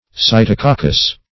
Search Result for " cytococcus" : The Collaborative International Dictionary of English v.0.48: Cytococcus \Cy`to*coc"cus\ (-k?k"k?s), n.; pl.
cytococcus.mp3